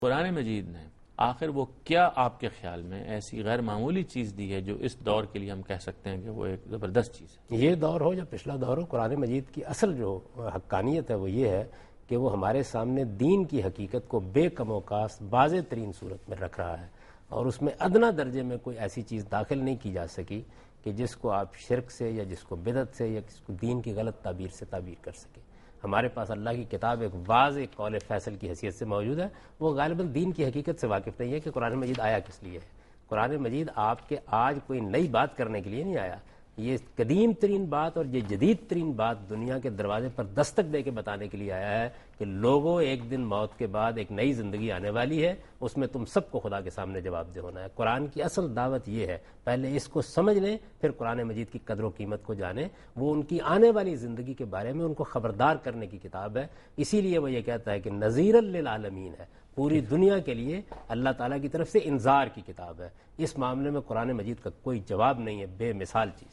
Category: TV Programs / Dunya News / Deen-o-Daanish / Questions_Answers /
during a talk show